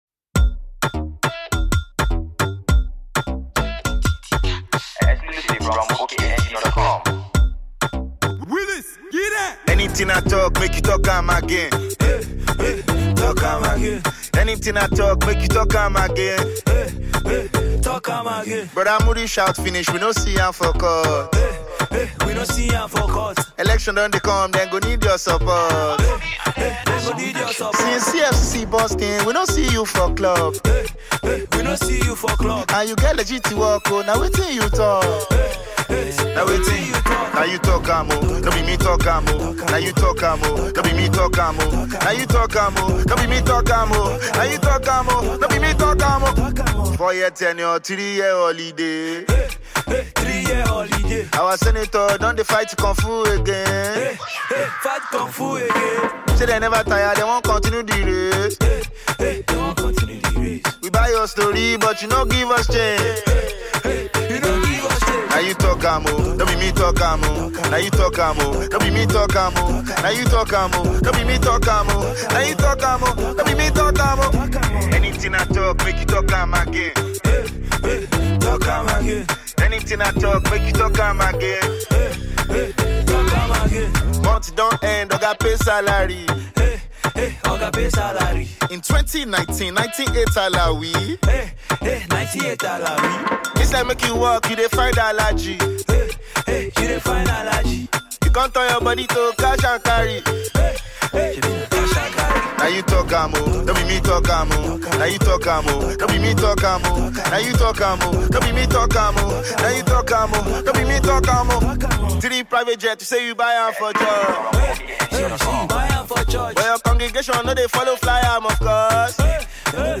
The talented Nigerian rapper